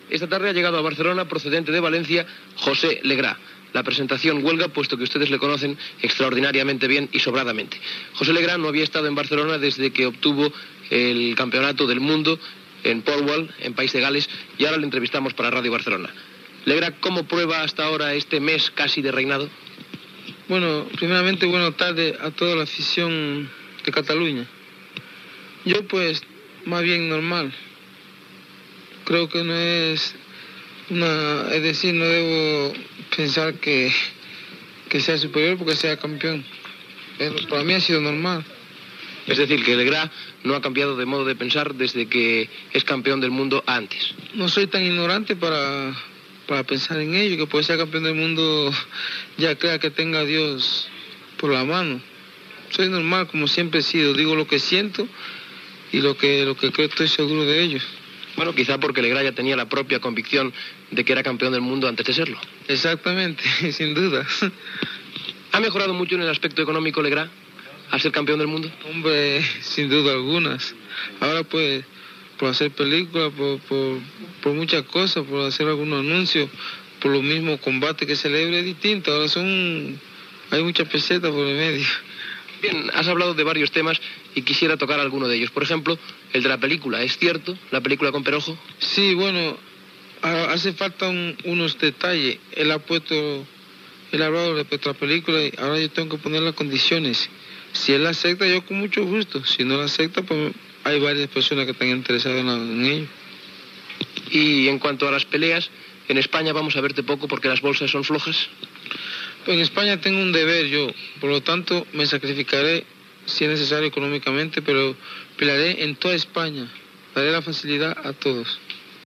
Entrevista al boxador José Legrá.
Esportiu